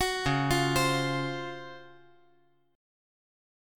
DbM11 Chord
Listen to DbM11 strummed